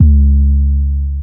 808s